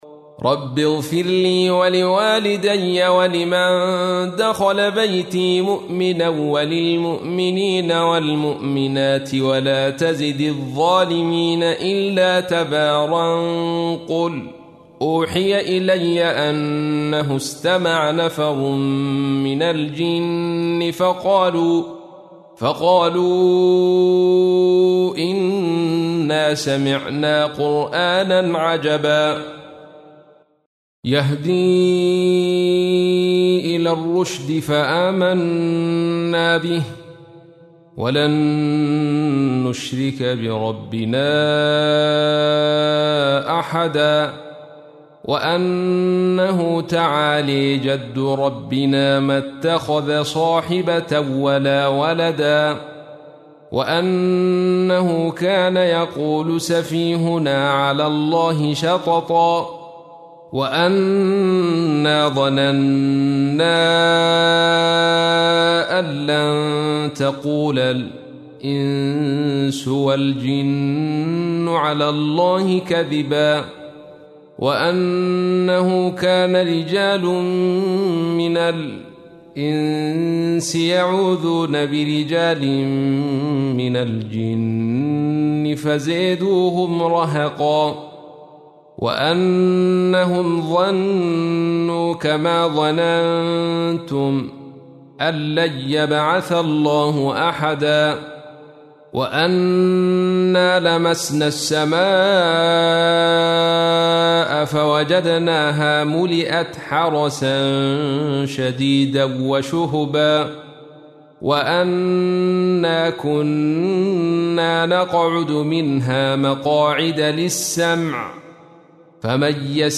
تحميل : 72. سورة الجن / القارئ عبد الرشيد صوفي / القرآن الكريم / موقع يا حسين